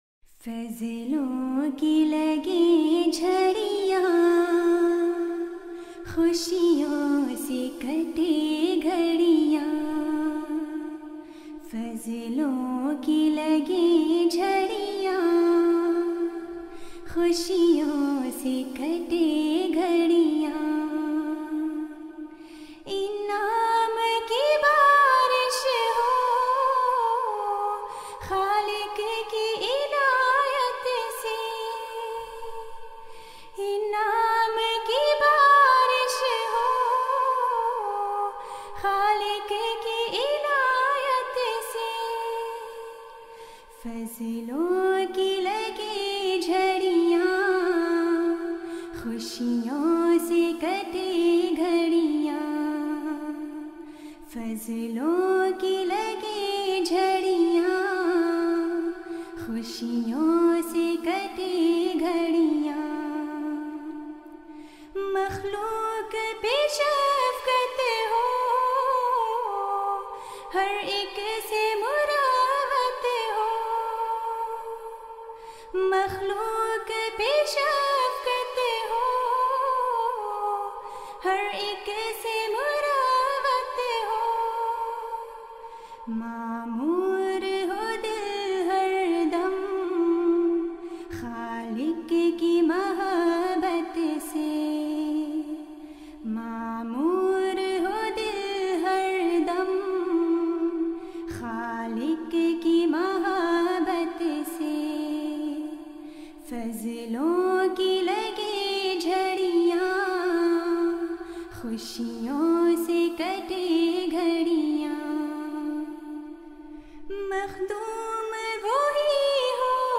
لائبریری منظوم کلام نظمیں (Urdu Poems) حضرت سیدہ نواب مبارکہ بیگمؓ پلے لسٹ Playlist دعائیں اور نصائح ۔ فضلوں کی لگیں جھڑیاں Fazlo ki lagi jarhian کلام حضرت سیدہ نواب مبارکہ بیگمؓ Poem by Hazrat Syeda Nawab Mubarika Begum آواز: ممبر لجنہ اماءاللہ Voice: Member Lajna Ima`illah جلسہ سالانہ جرمنی ۲۰۱۸ء Jalsa Salana Germany 2018 mp3